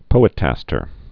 (pōĭt-ăstər)